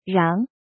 怎么读
ráng xiāng
rang2.mp3